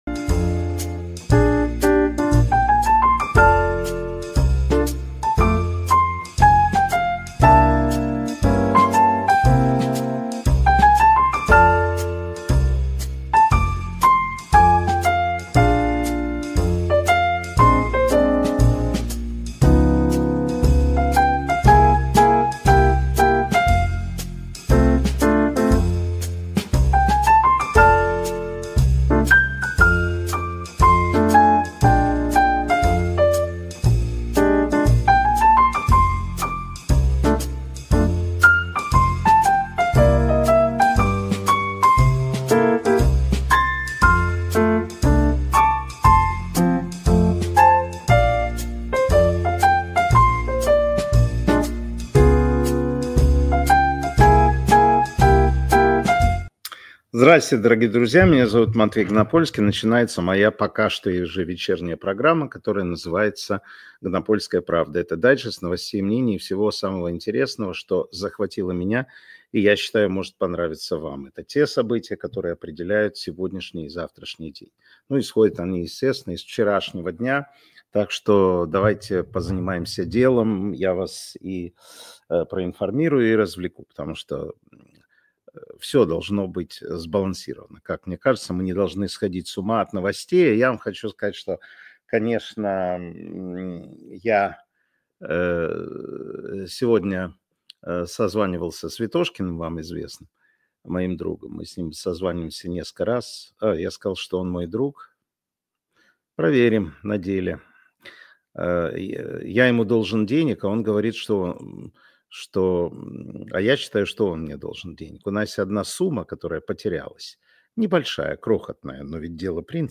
Информационно-аналитическая программа Матвея Ганапольского.